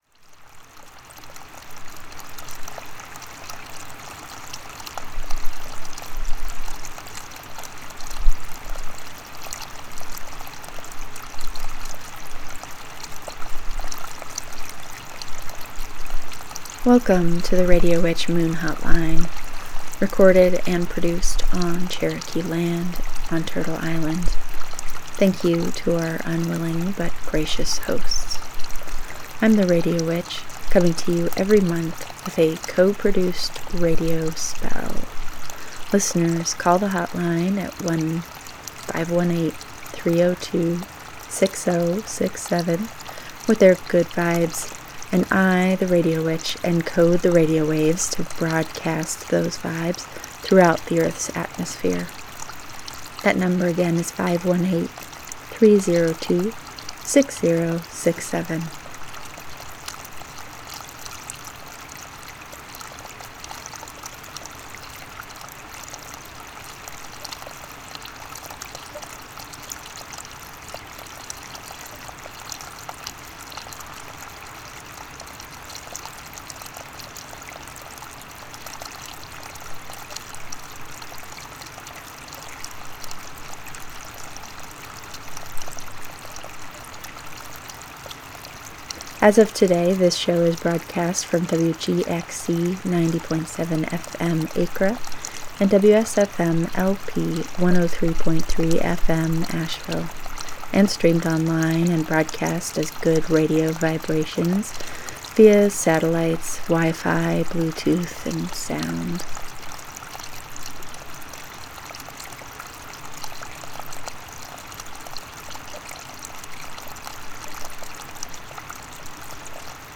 Listeners are invited to participate by calling into the Radio Witch Moon Hotline number.